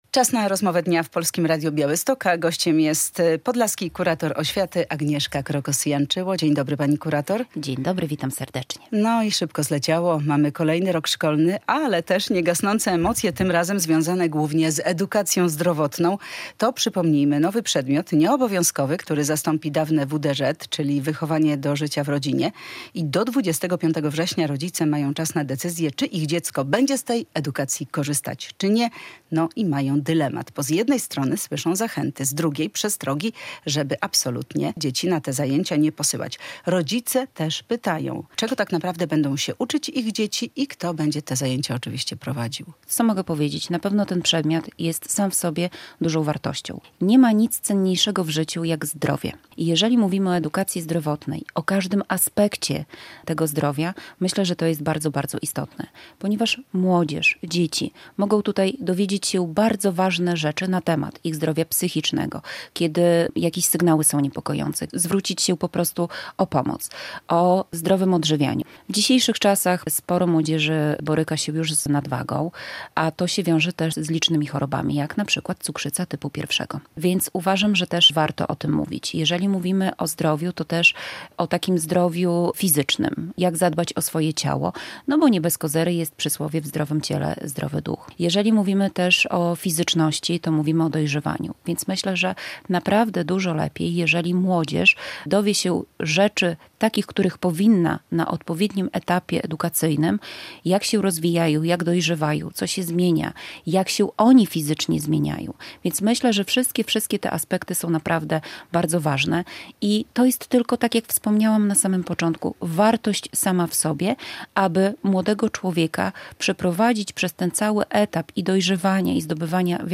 Radio Białystok | Gość | Agnieszka Krokos-Janczyło - podlaska kurator oświaty